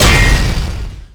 bladeslice4.wav